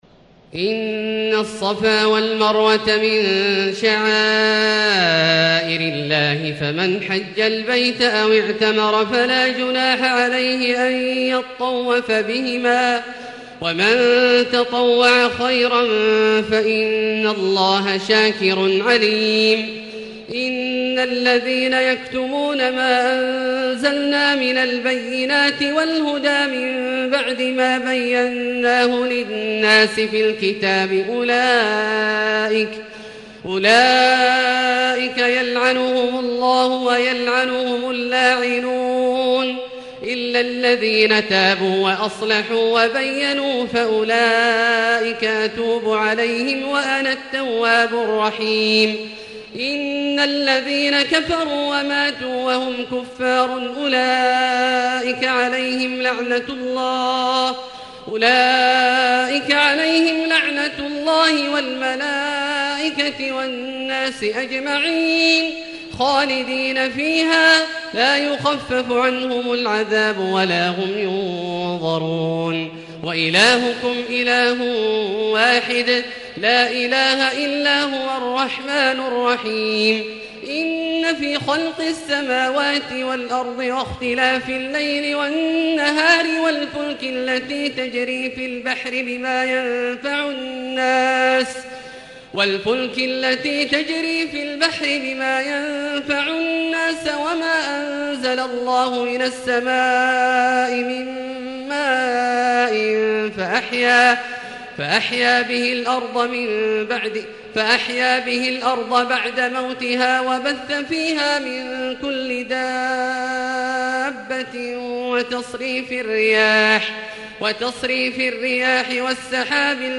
تراويح الليلة الثانية رمضان 1440هـ من سورة البقرة (158-227) Taraweeh 2 st night Ramadan 1440H from Surah Al-Baqara > تراويح الحرم المكي عام 1440 🕋 > التراويح - تلاوات الحرمين